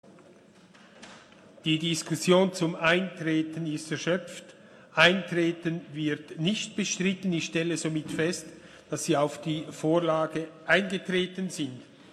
Session des Kantonsrates vom 17. bis 19. Februar 2020
Baumgartner-Flawil, Ratspräsident, stellt Eintreten auf die Vorlage fest.